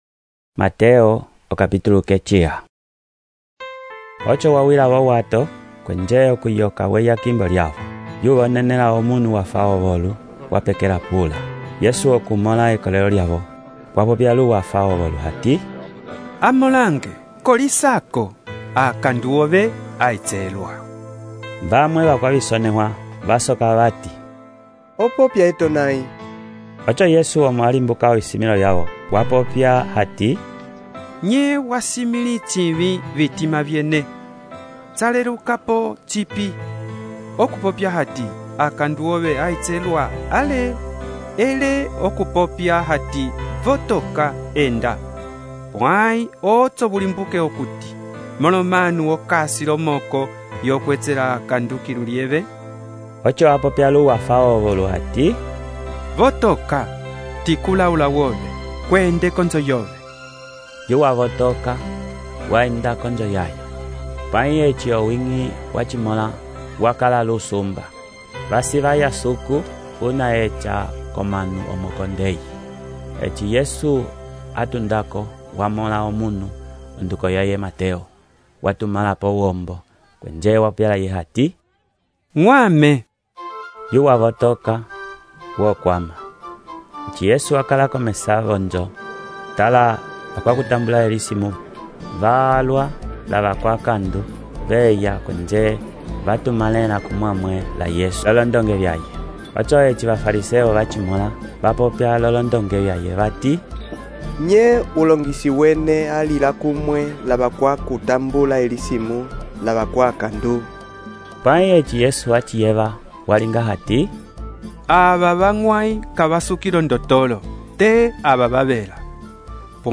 texto e narração , Mateus, capítulo 9